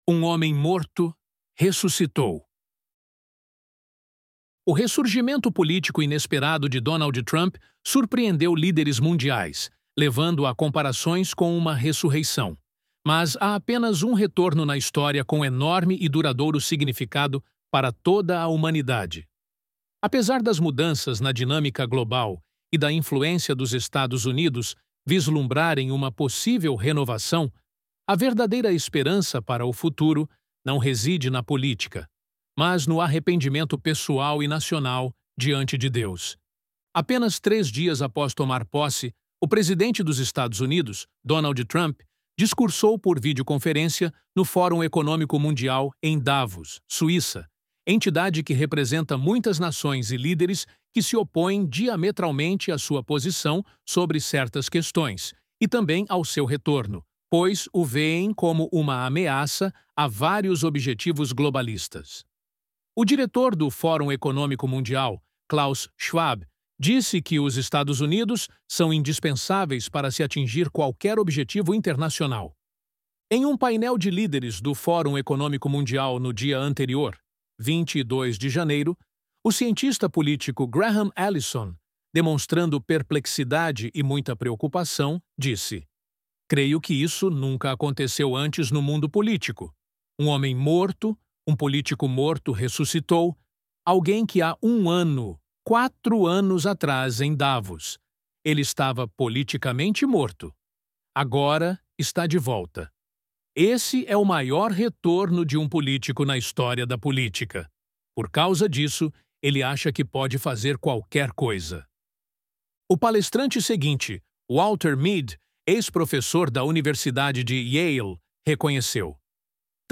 Loading the Elevenlabs Text to Speech AudioNative Player...